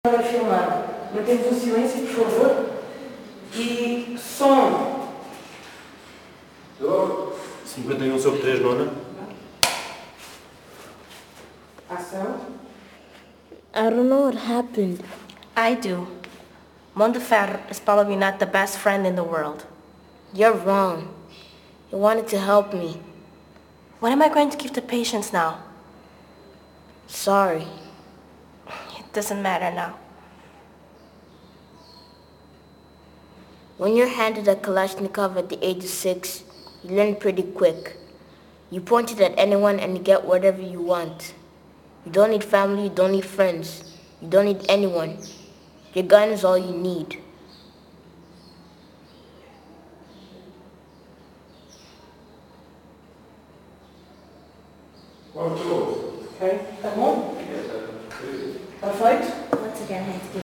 La République des enfants – 51 / 3 t9 – perche, micro d’appoint au centre